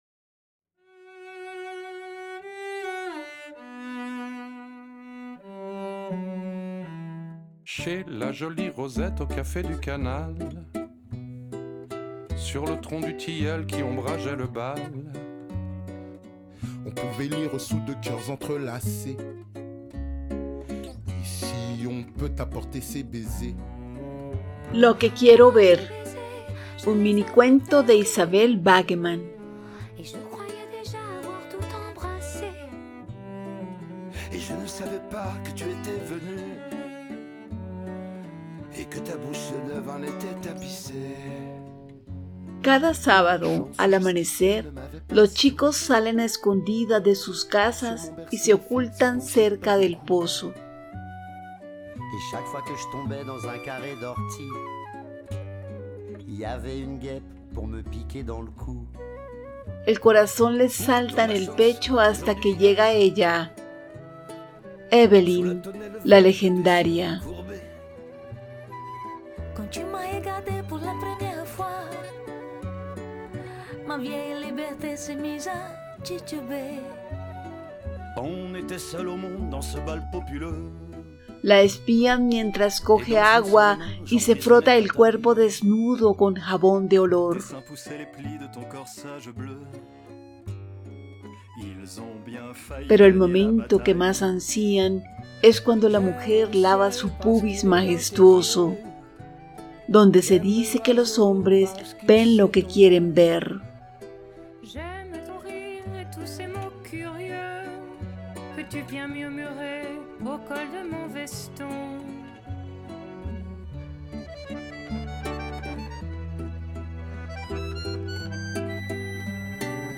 Tema musical: “Au café du canal” del músico francés Pierre Perret, interpretado por sus amigos, el colectivo La Tribu de Pierre Perret y orquestado por Les Ogres de Barback (2017), como homenaje a la trayectoria del autor.